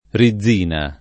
[ ri zz& na ]